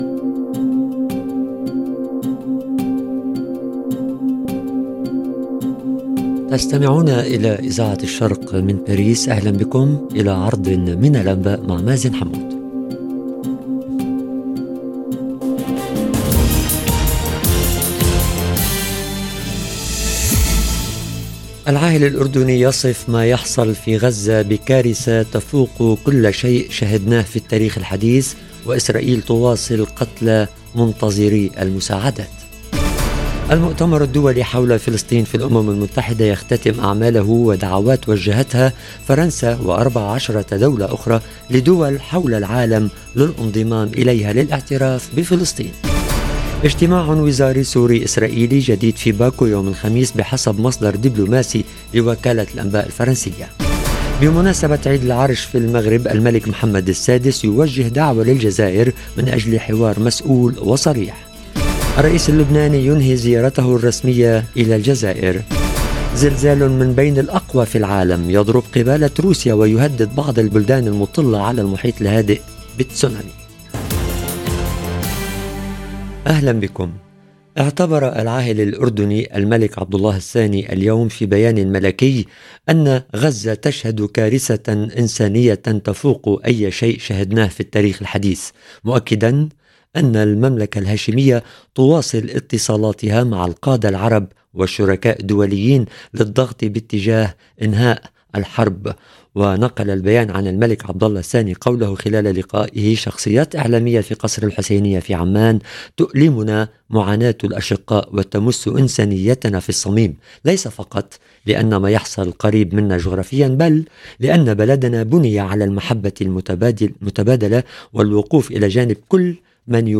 نشرة أخبار المساء: العاهل الأردني يصف ما يحصل في غزة بكارثة، واجتماع وزاري سوري اسرائيلي في باكو الخميس - Radio ORIENT، إذاعة الشرق من باريس